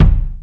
Kick (209).WAV